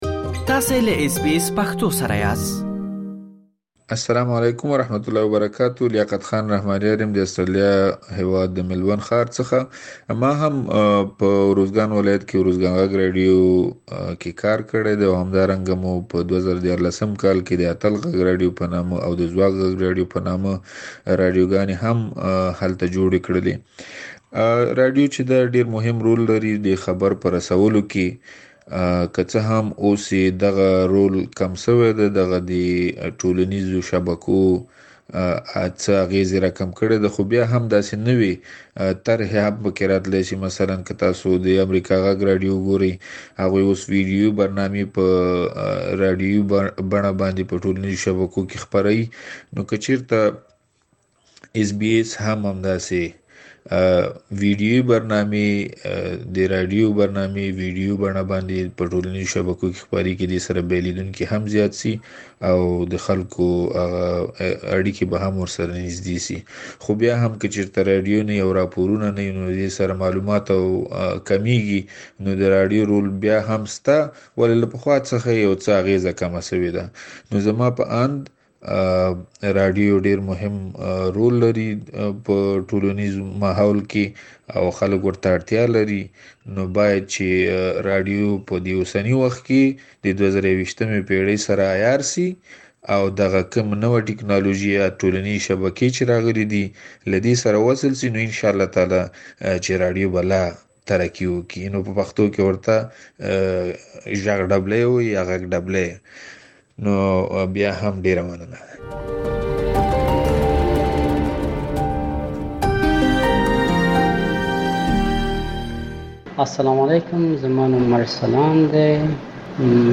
په آسټرالیا کې یو شمېر مېشتو افغانانو د راډیو د نړیوالې ورځې په مناسبت له اس بي اس پښتو راډیو سره خپل نظرونه شریک کړي.